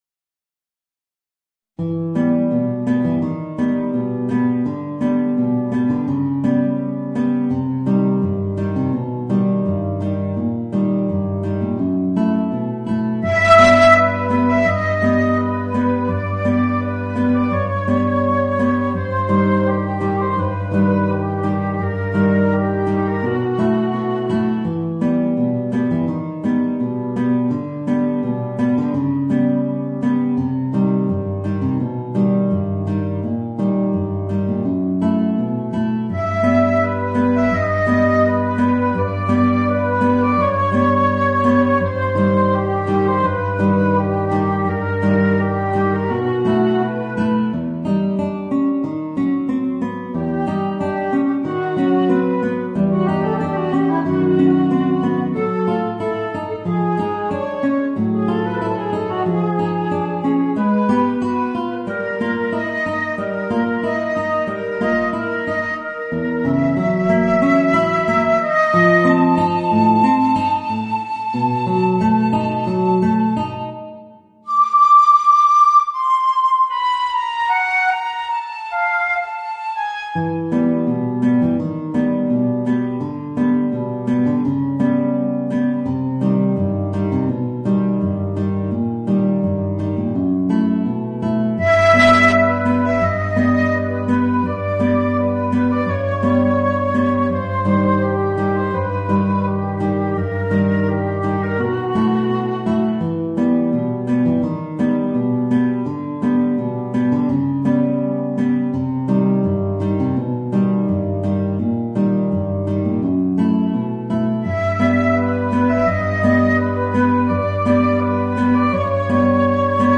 Voicing: Flute and Guitar